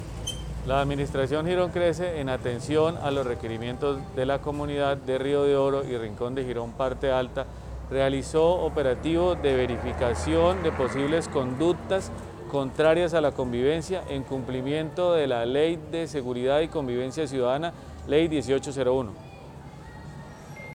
Cristian Calderón - Secretario de Seguridad, Convivencia y Gestión del Riesgo.mp3